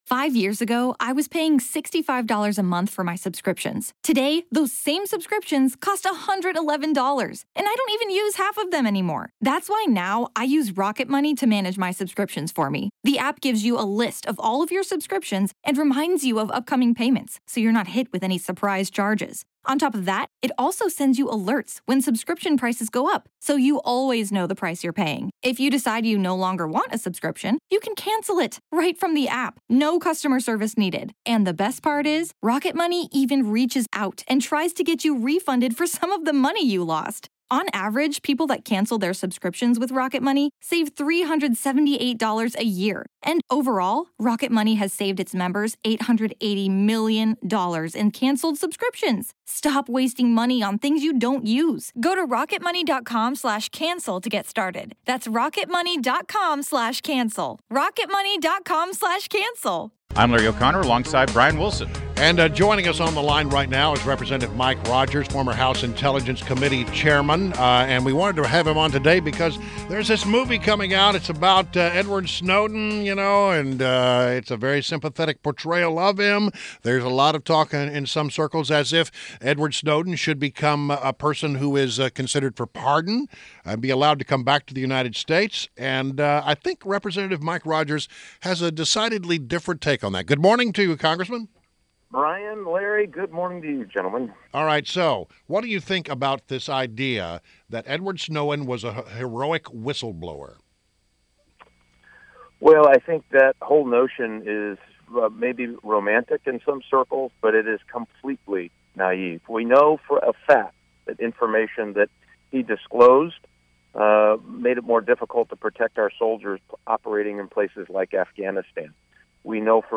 WMAL Interview - REP. MIKE ROGERS - 09.16.16